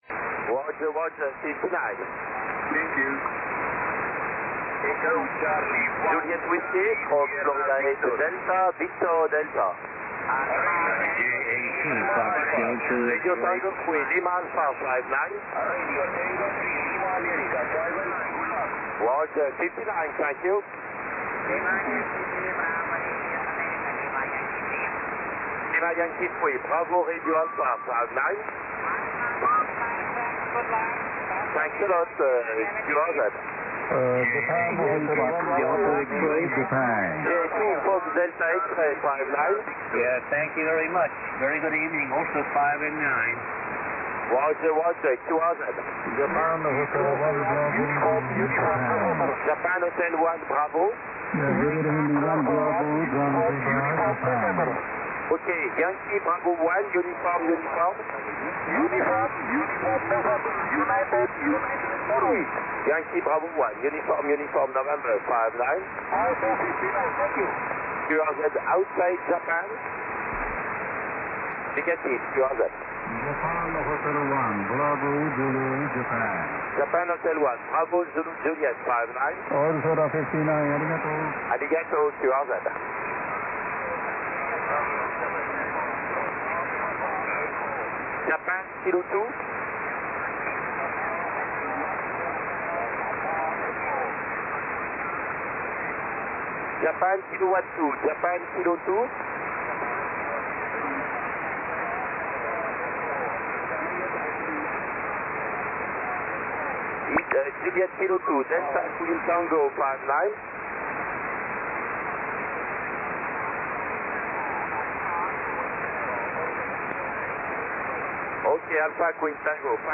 21.239MHz SSB